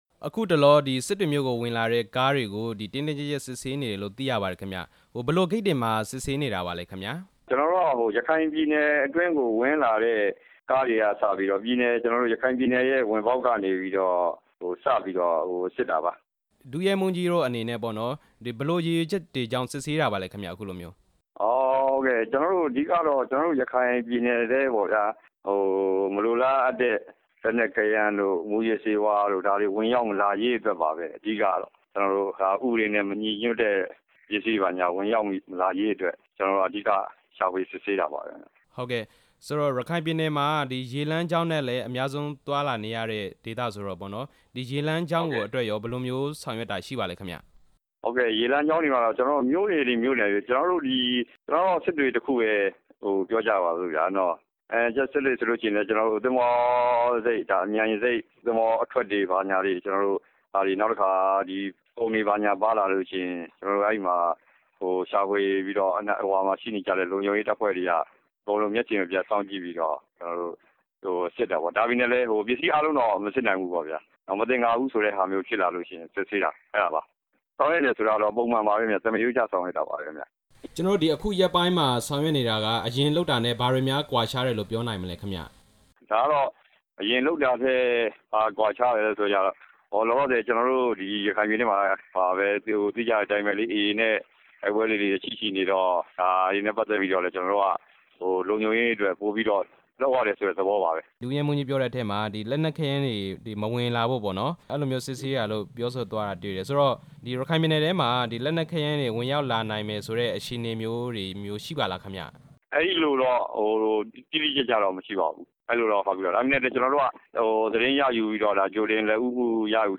ရခိုင်ပြည်နယ်မှာ အစိုးရတပ်မတော်နဲ့ ရက္ခိုင့်တပ်မတော်(AA)တို့ တိုက်ပွဲတွေဖြစ်ပွားခဲ့တာကြောင့် ရခိုင်ပြည်နယ်က လမ်းတွေမှာလုံခြုံရေးကို တင်းတင်းကျပ်ကျပ် ဆောင်ရွက်နေတယ်လို့ စစ်တွေခရိုင်ရဲတပ်ဖွဲ့မှူး ဒုတိယရဲမှူးကြီးဝင်းနောင်က ပြောပါတယ်။